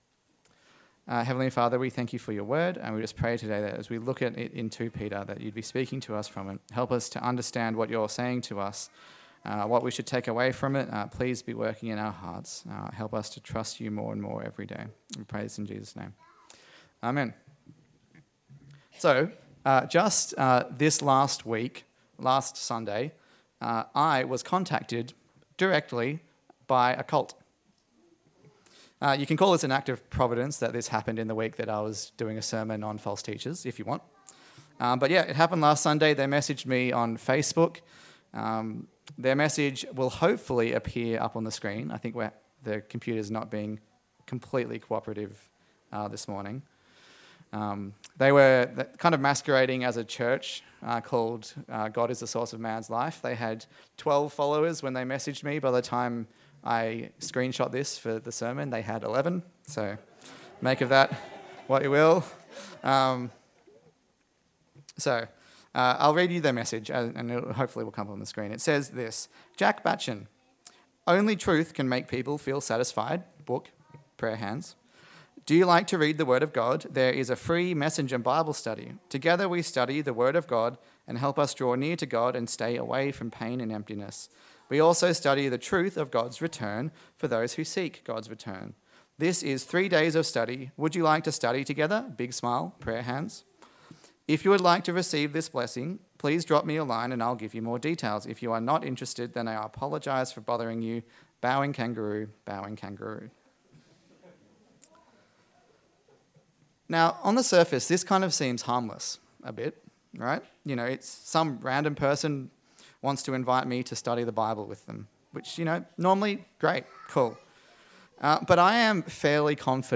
Service Type: Sunday Service A sermon in the series on the second letter of Peter